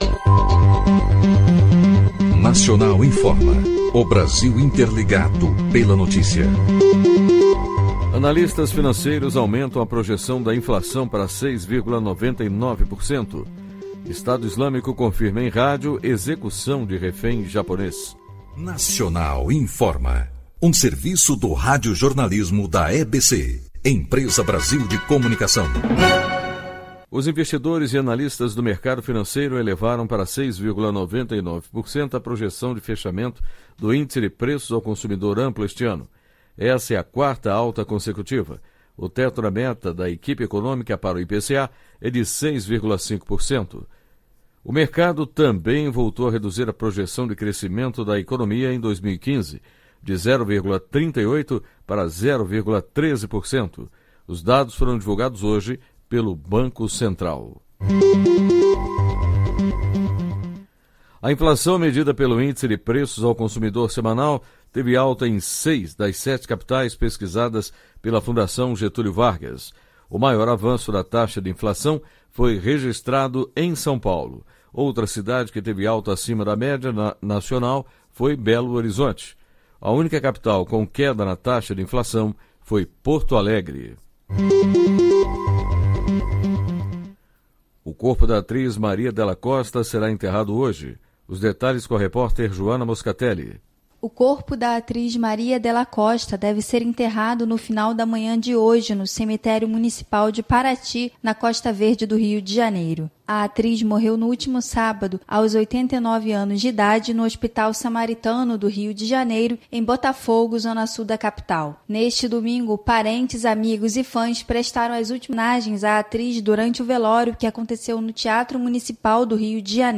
Nacional Informa : Boletim de notícias veiculado de hora em hora, com duração de até 4 minutos.